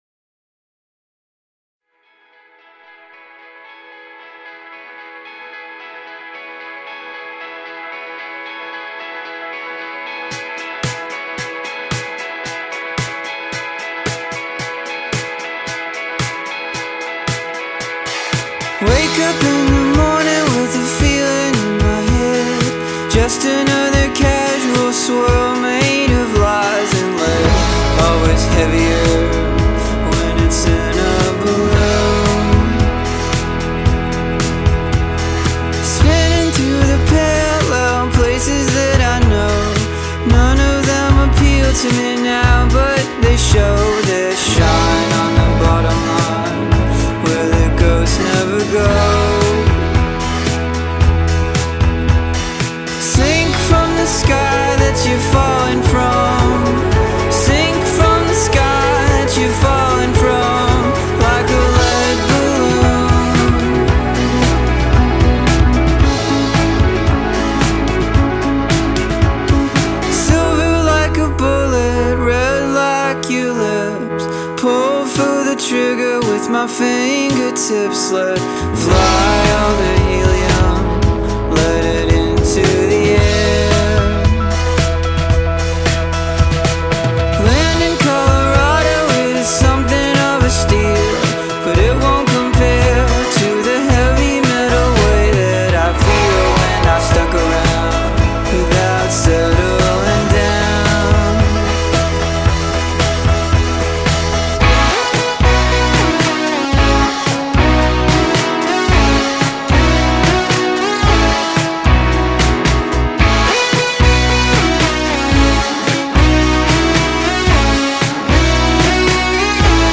Philly psych-pop four-piece